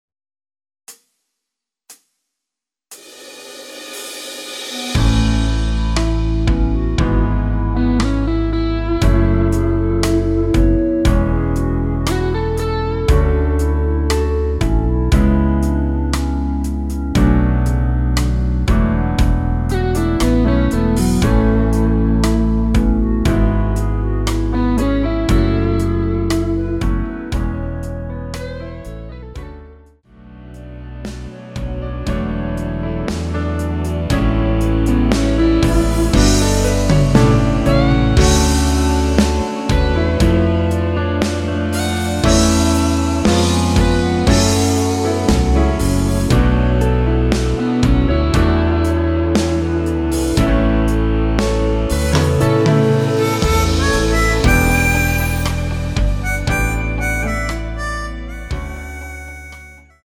전주없이 시작하는 곡이라 카운트 4박 넣어 놓았습니다.(미리듣기 참조)
원키 멜로디 포함된 MR입니다.
앞부분30초, 뒷부분30초씩 편집해서 올려 드리고 있습니다.
중간에 음이 끈어지고 다시 나오는 이유는